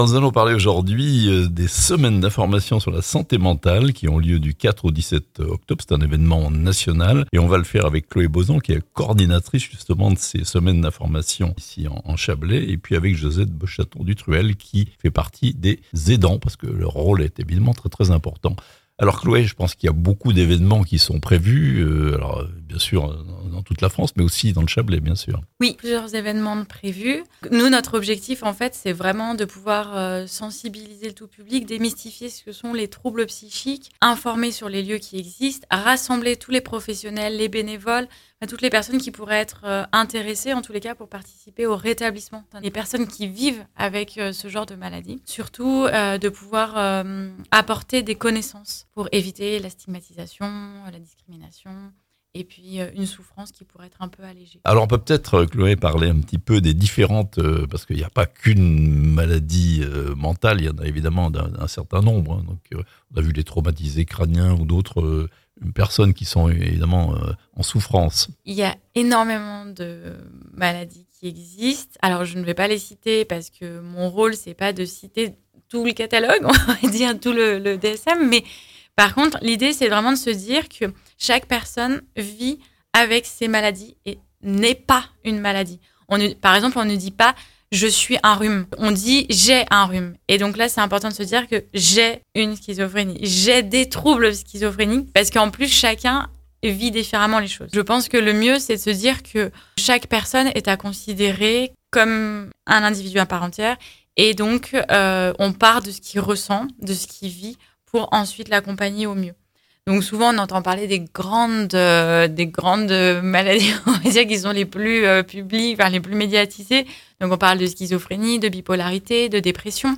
Les Semaines d'Information sur la Santé Mentale (interviews)